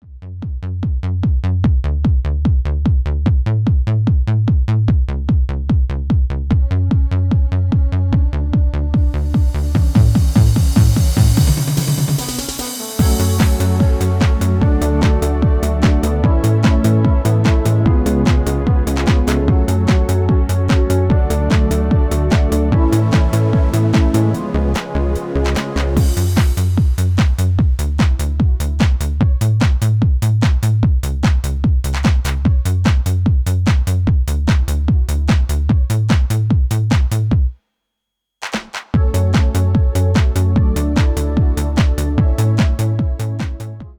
Extended MIDI File Euro 11.75
Demo's zijn eigen opnames van onze digitale arrangementen.